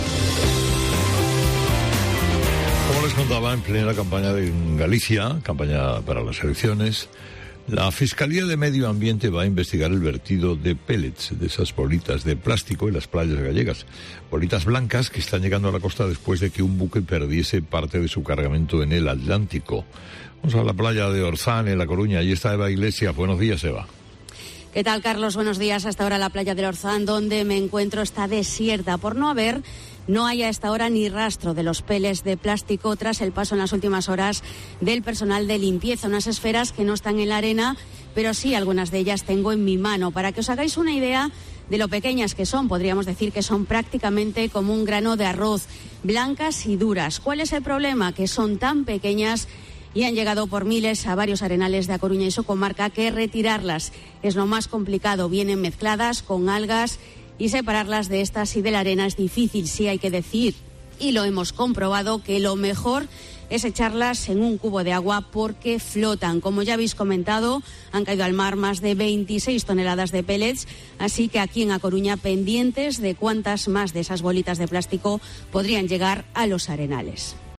desde la playa del Orzán